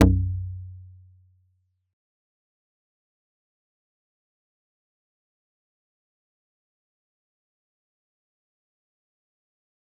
G_Kalimba-B1-mf.wav